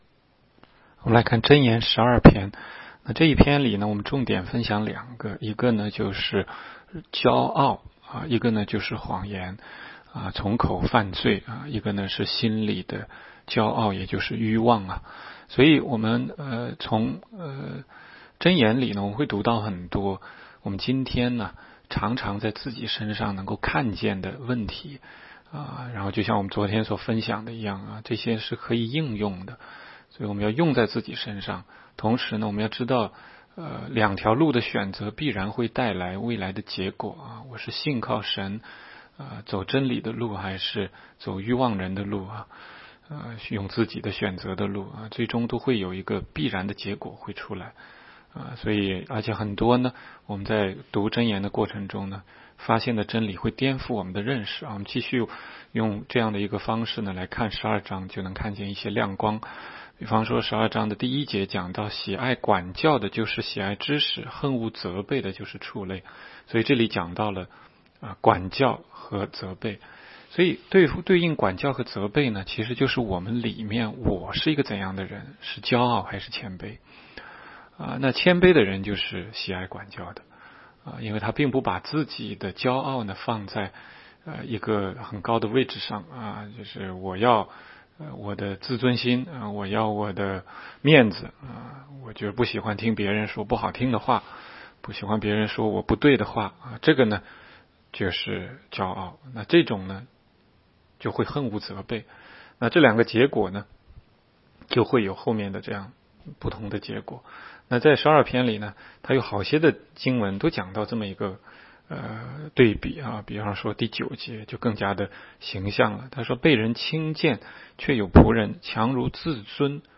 16街讲道录音 - 每日读经 -《 箴言》12章